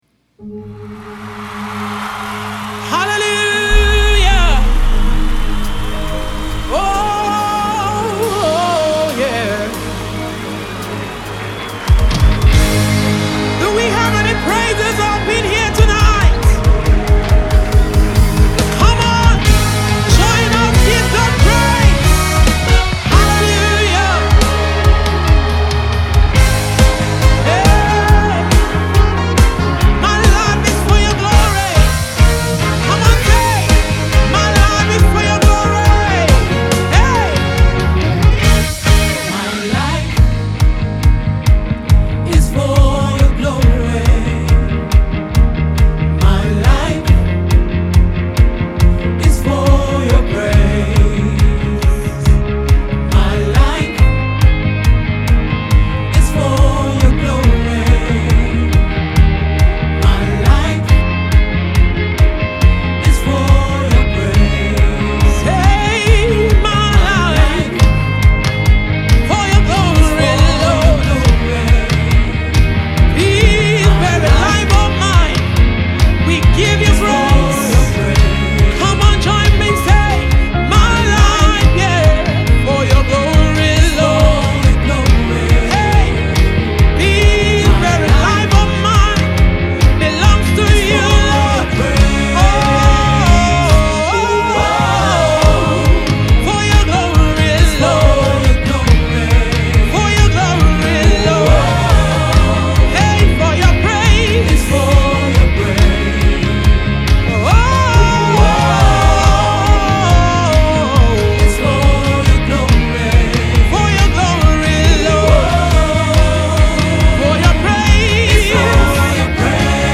festive undertone and is apt for the season.
It is a contemporary Christian music, one that will
Tags:    Gospel Music,     Naija Music